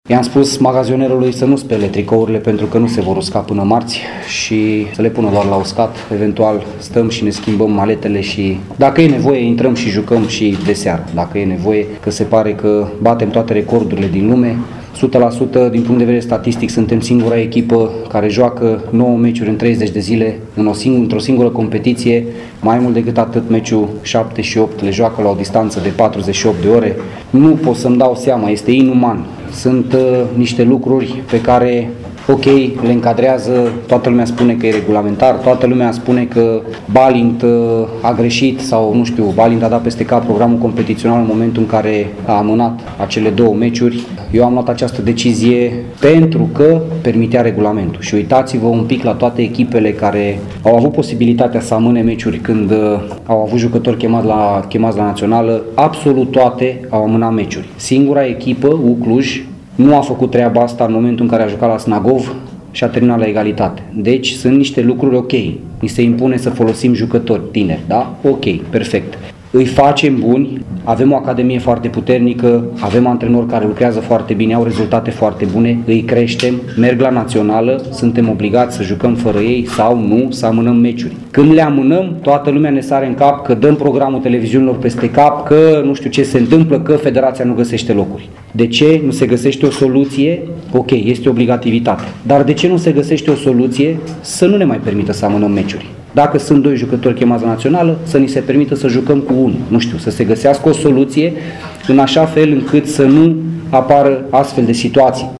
Arădenii încheie turul primii, cu 35 de puncte, și revin în fața fanilor, marți, la ora 14.00, în restanța cu Concordia Chiajna. Balint este supărat din pricina distanței foarte mici dintre meciul de astăzi și restanța de marți: